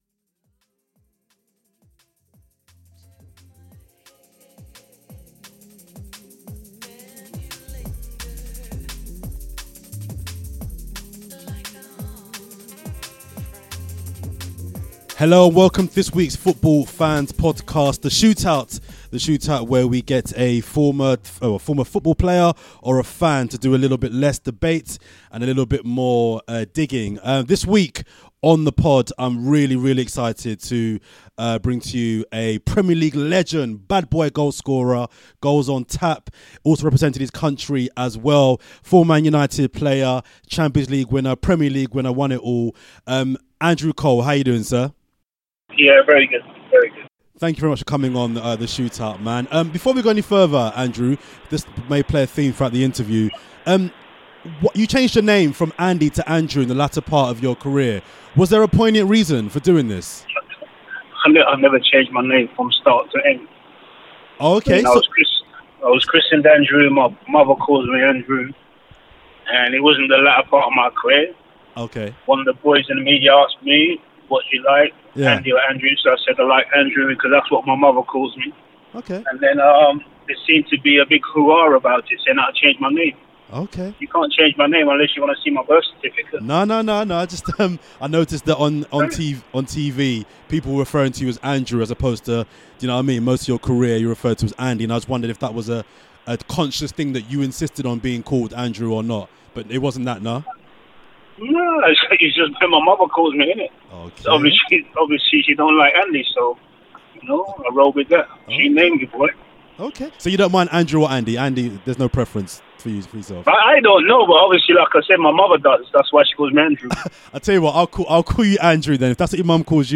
This month we speak to former Manchester United, Newcastle United and England striker, Andrew Cole.